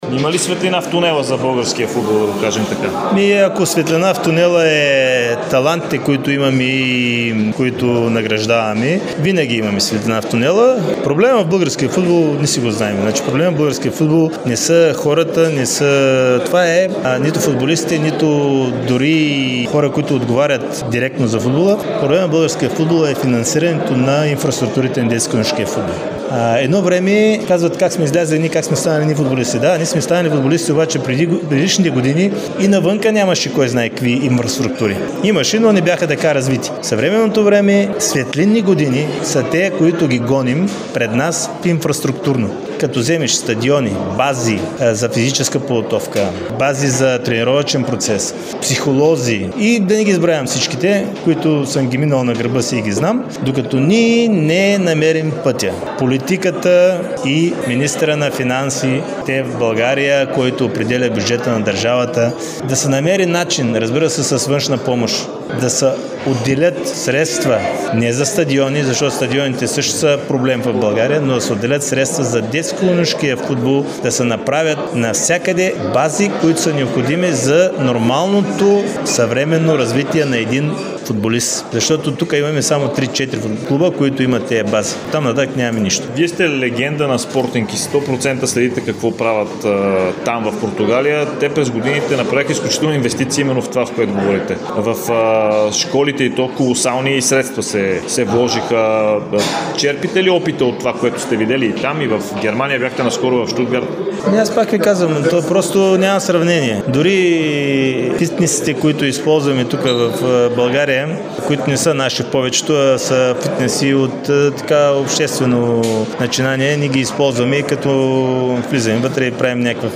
Легендата на българския футбол – Красимир Балъков, даде интервю за Дарик радио и dsport.